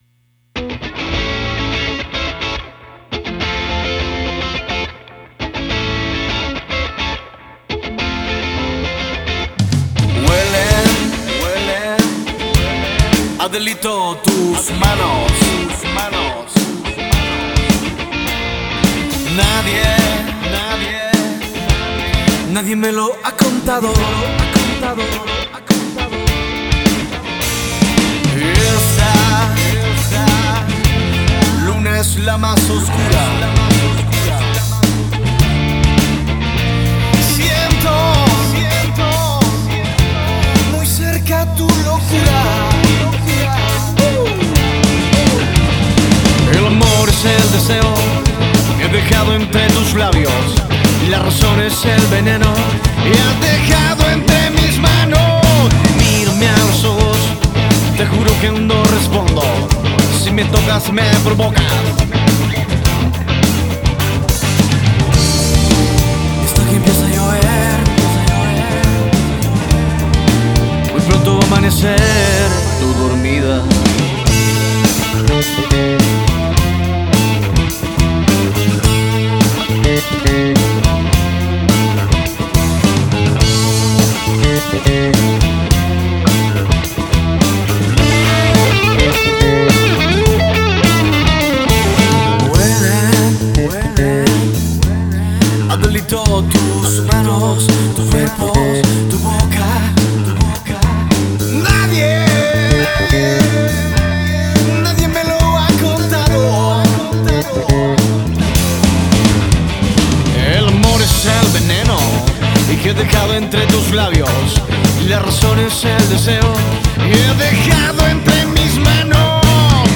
Rock en español y Pop latino
Rock en Español